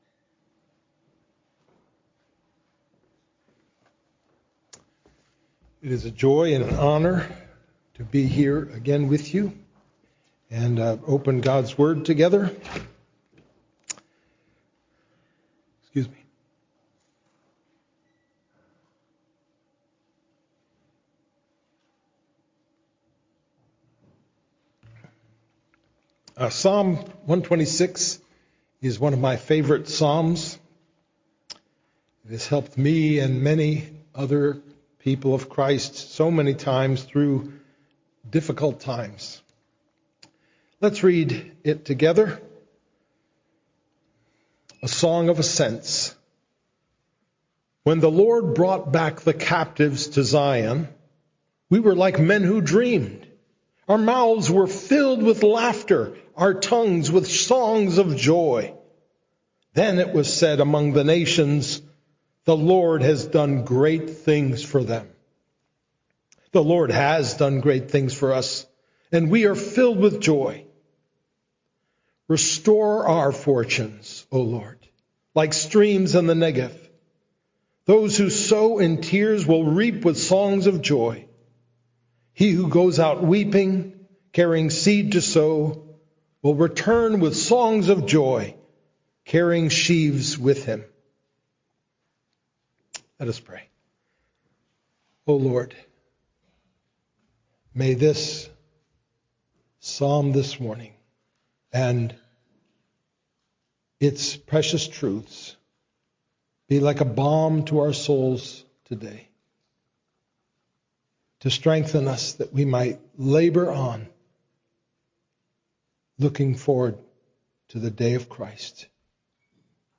Sow in Tears, Reap in Joy: Sermon on Psalm 126